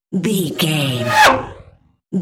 Sci fi vehicle pass by fast
Sound Effects
futuristic
pass by
vehicle